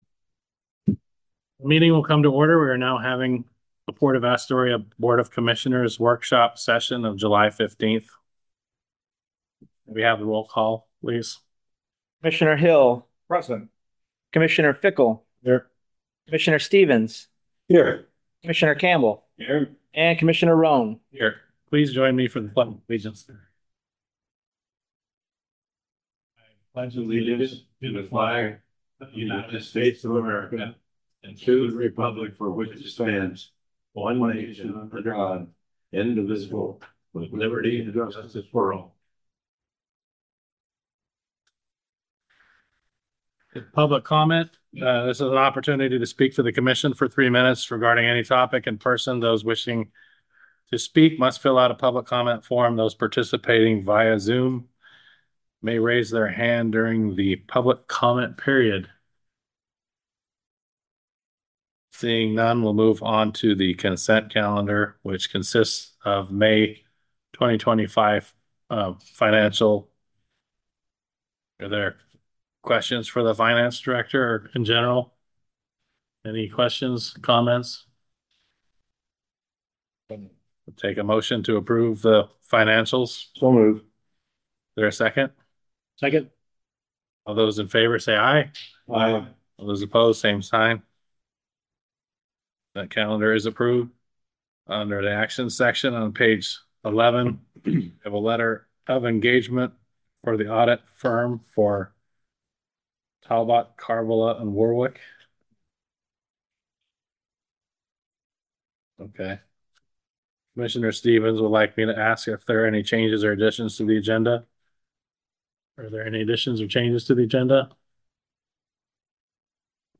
Commission Meeting
422 Gateway Avenue Suite 100, Astoria, OR, at 4 PM